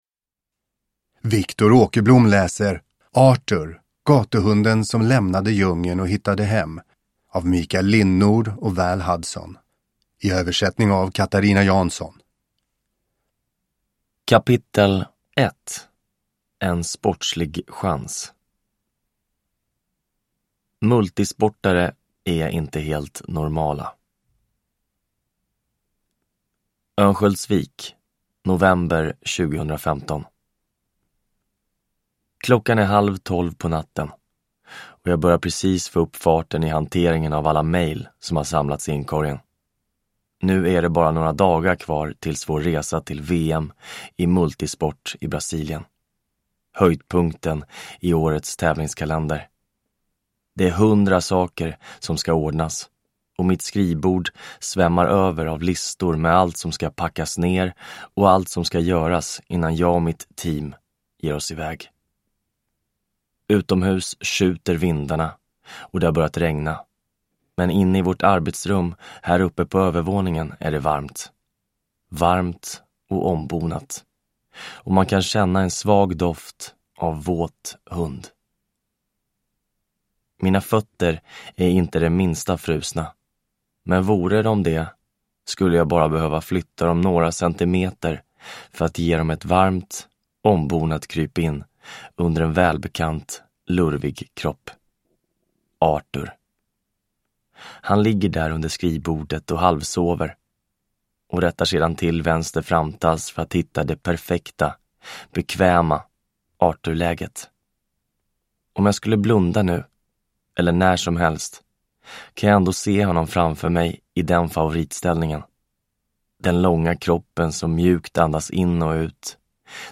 Arthur : gatuhunden som lämnade djungeln och hittade hem – Ljudbok